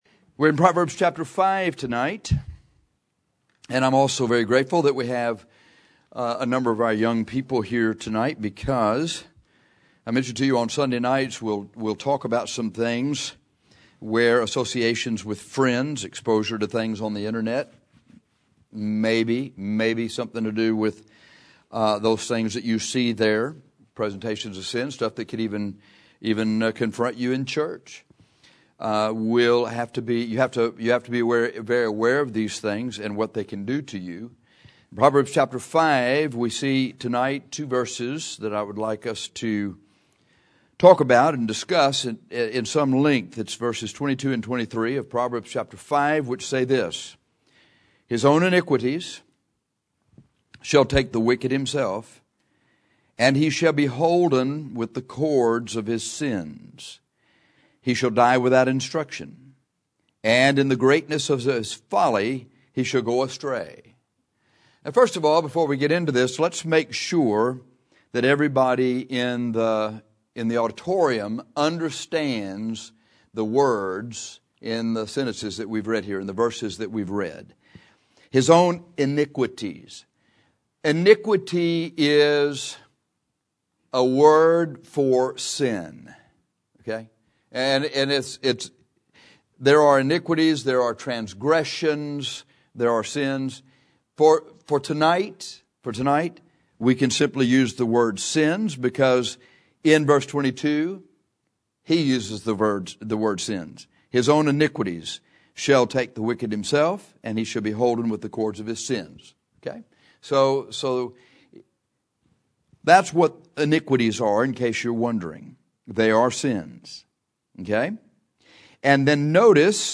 This is not a sermon, primarily, on how to get victory over sin.